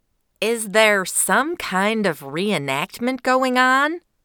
On this occasion, “flat, mid-Western” accent was called out in the text, so I got to play around and give that a shot.
It’s campy, and the beginning is wrong, but the end is close. So this lady is actually a midwesterner who has lived…in the south for awhile.
DITDflatmidwestern.mp3